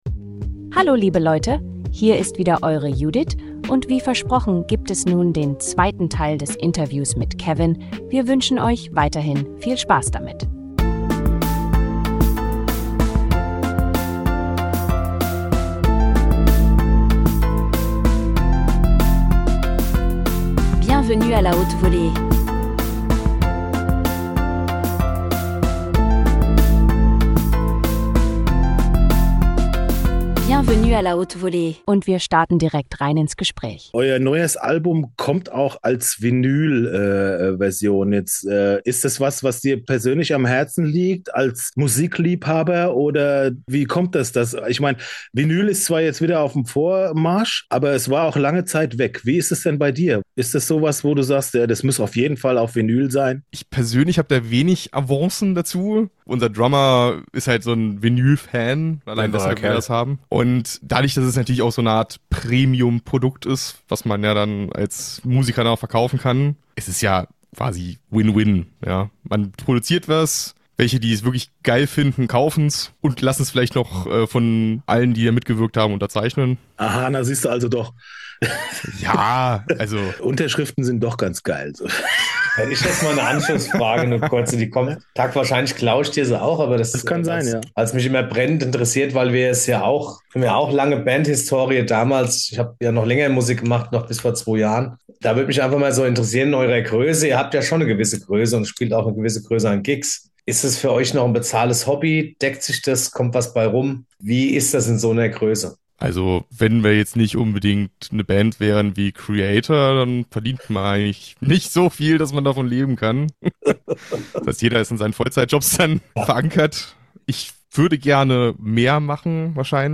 Folge 127.2 – Death Metal Part 2 oder: Das Interview, das zu brutal gut für nur eine Episode war Weiter geht’s mit Teil 2 unseres Death-Metal-Deepdives